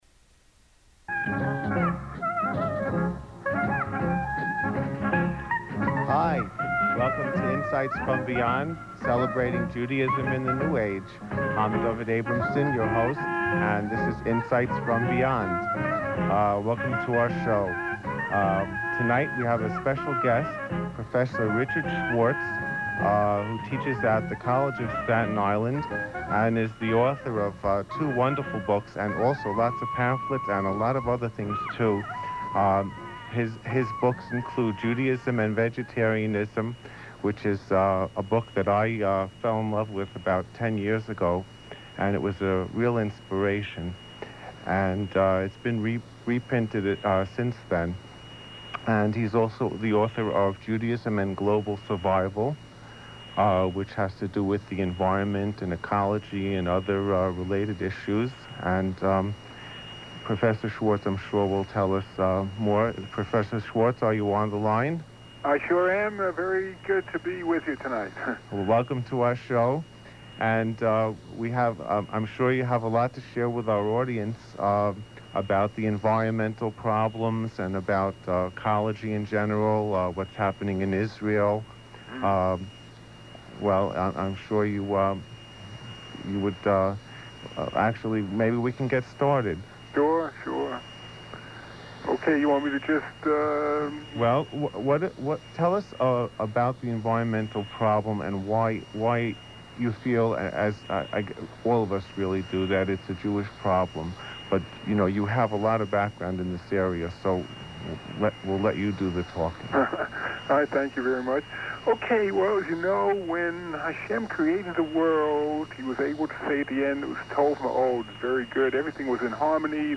..from the radio broadcast Insights from Beyond.